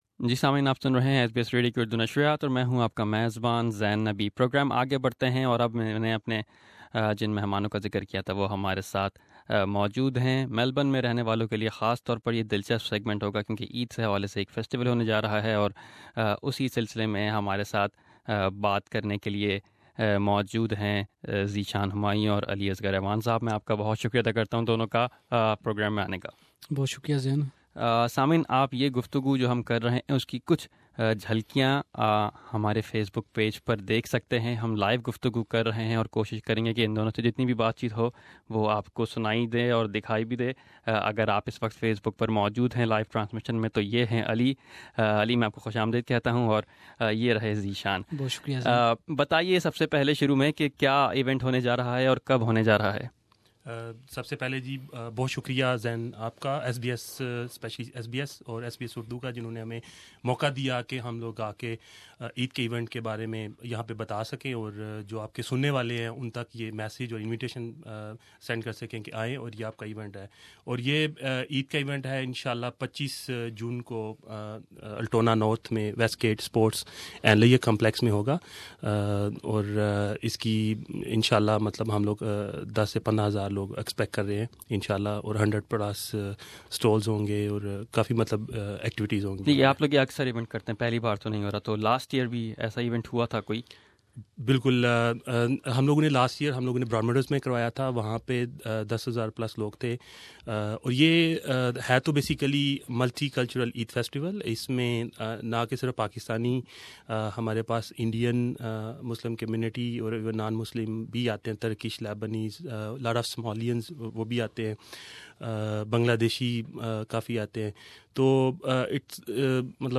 Organisers during an interview with SBS Source: SBS